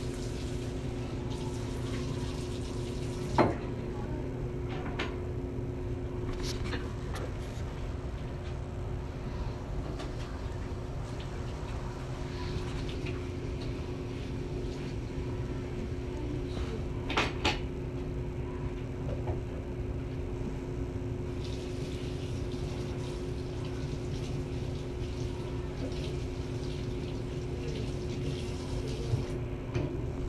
Field Recording 2
Sounds Heard: plastic container being moved, knife being picked up, food hitting tin foil, footsteps, tin foil rattling, tray sliding into metal rack, air fryer door opening and closing, buttons beeping, air fryer turning on, plastic being placed on the counter, sink turning on and water running.